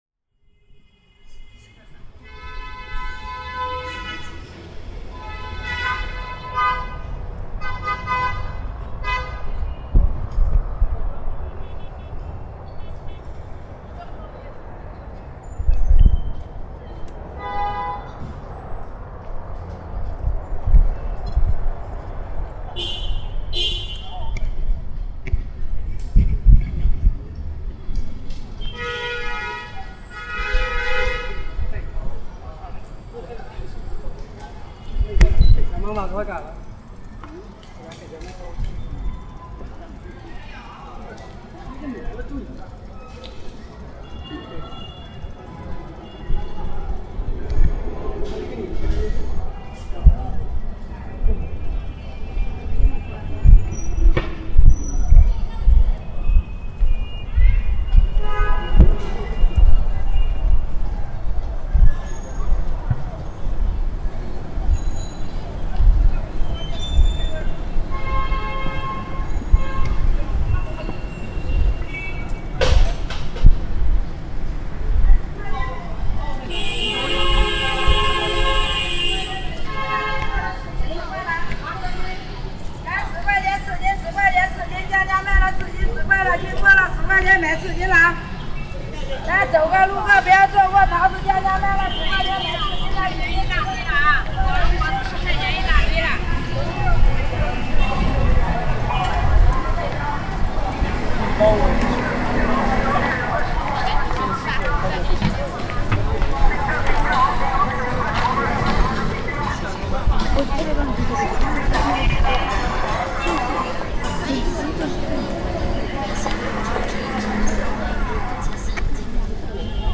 Focusing one’s attention on the sounds of Baishizhou enabled one to escape the idea of Baishizhou and experience something unforgettable, something that could only have happened then and there. Experienced in silence, Baishizhou is another landscape.